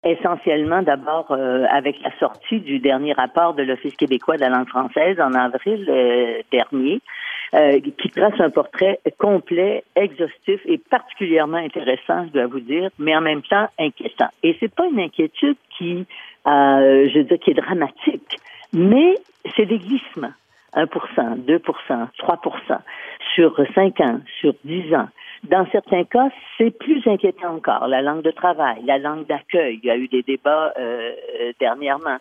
Pauline Marois fue entrevistada sobre ese tema este miércoles 4 de septiembre en el programa matutino Tout un matin (Toda una mañana) de la radio francesa de Radio Canadá y explica su preocupación.